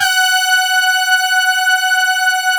BRASS2 MAT.4.wav